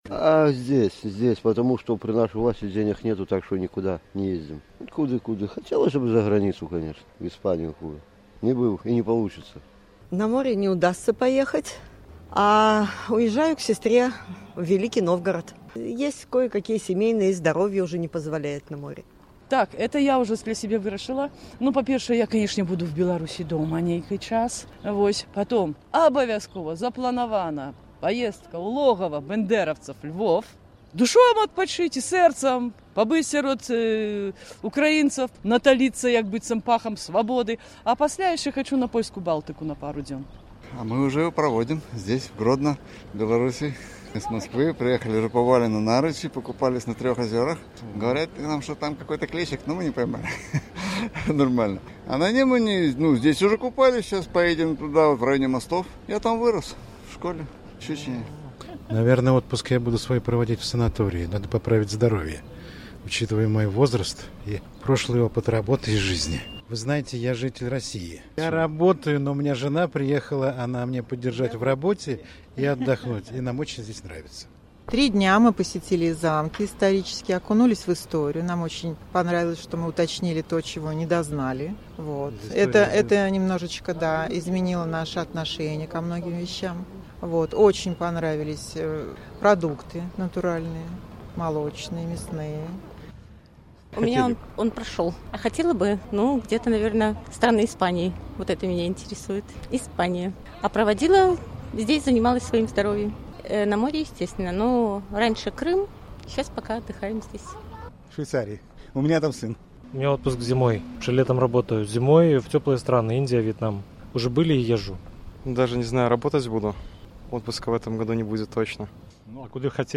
Vox populi
Дзе вы плянуеце сёлета адпачыць і дзе марыце? З такім пытаньнем карэспандэнт Свабоды зьвярнуўся да Гарадзенцаў.
Іншыя інтанацыі пачуліся ў адказах дзьвюх жанчын, якія плянуюць выехаць за межы Беларусі, але ў розных накірунках: